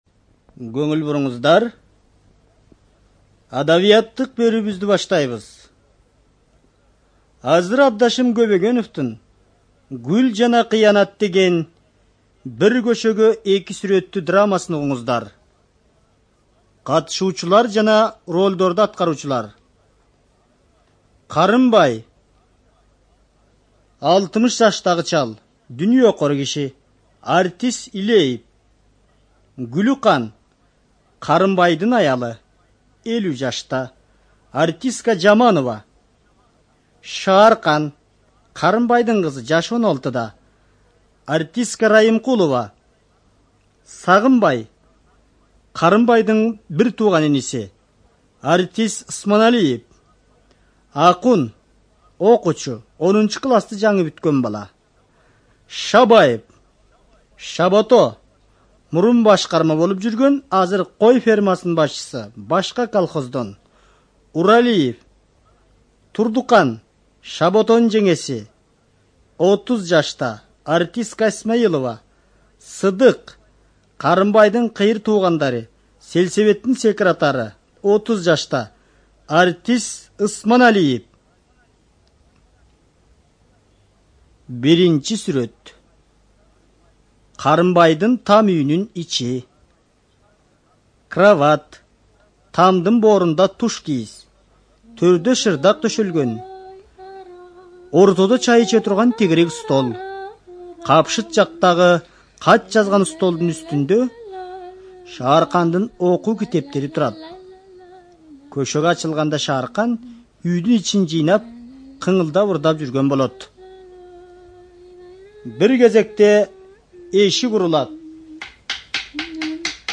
ЖанрРадиоспектакли на кыргызском языке